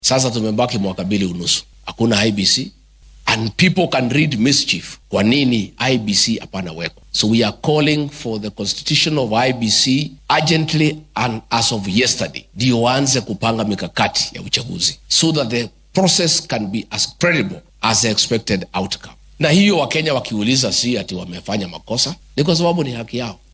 Hadalkan ayuu maanta jeediyay xilli uu munaasabad kaniisad ah uga qayb galay deegaanka Kasarani ee ismaamulka Nairobi.